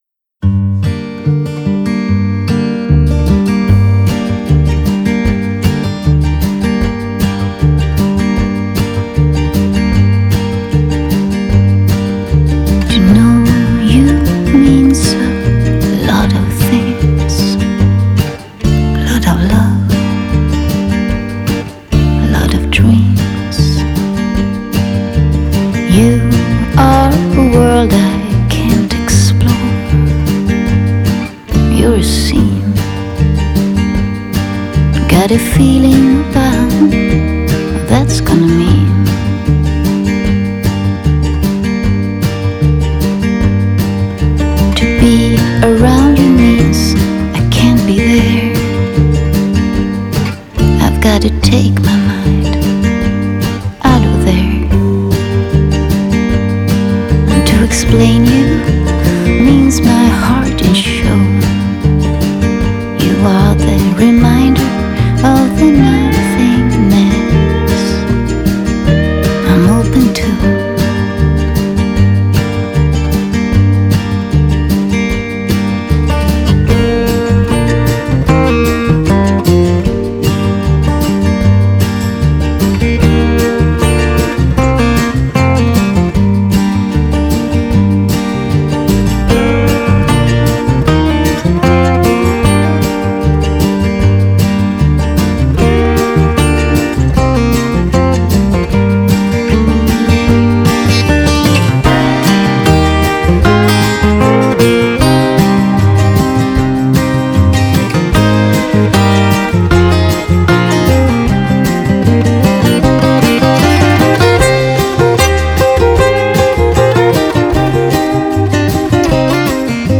장르: Rock
스타일: Acoustic